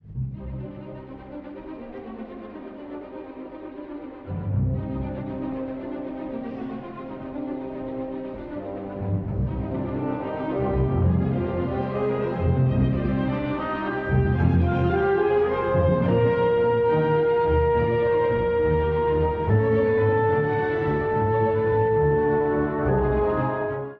第1楽章｜闇をうごめく循環主題と深いアダージョ
古い音源なので聴きづらいかもしれません！（以下同様）
闇を彷徨うような前半から、祈るような後半への移りが印象的です。
冒頭から強い不安定さと緊張感が生まれます。
やがて音楽は、静けさと広がりのあるアダージョ（Des-dur）の世界へ。
弦がゆったりと旋律を奏で、管楽器がそれに呼応するように重なっていきます。